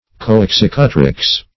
Coexecutrix \Co`ex*ec"u*trix\, n. A joint executrix.